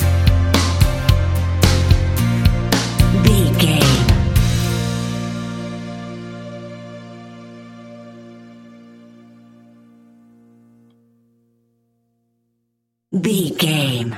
Aeolian/Minor
fun
energetic
uplifting
cheesy
guitars
bass
drums
organ